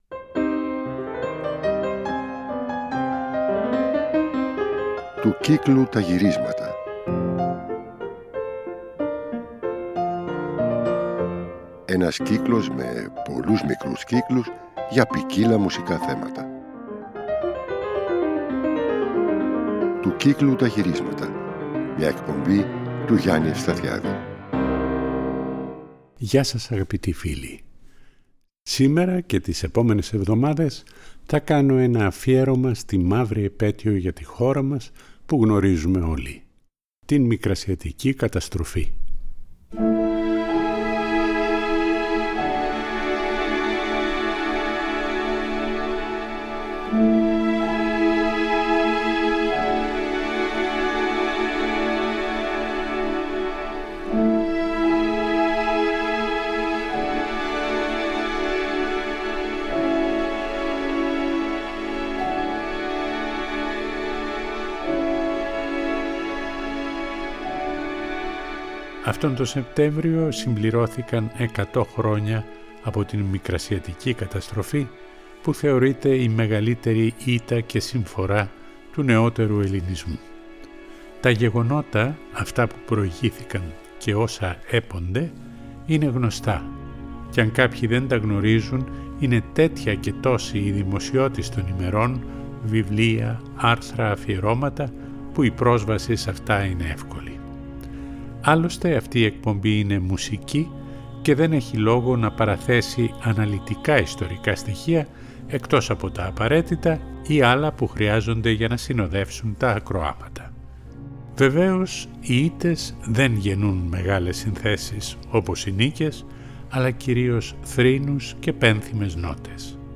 Η πρώτη εκπομπή ξεκινά από παλιά, την άλωση της Πόλης, με τουρκικές και ελληνικές ορχηστρικές συμφωνίες και ποντιακούς θρήνους της αλώσεως που ταιριάζουν και στην καταστροφή της Σμύρνης.
Ασχολείται πολύ και με την κρίσιμη πολεμική δεκαετία 1910-20 με εμβατήρια και θούρια του πυροβολικού, του ναυτικού και των ευζώνων.
Θα ακουστεί επίσης η φωνή του Βενιζέλου, η επιστολή του για την εκλογική ήττα του 1920 ακόμα και ο ίδιος να τραγουδά ένα ριζίτικο!